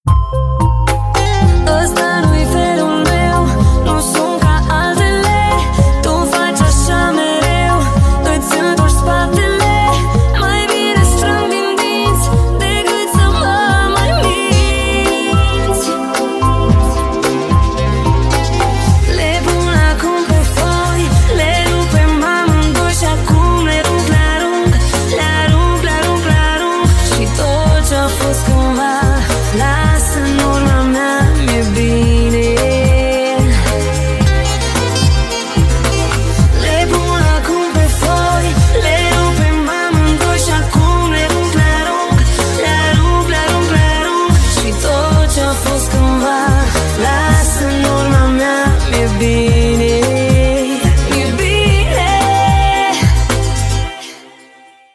Танцевальные
dance, vocal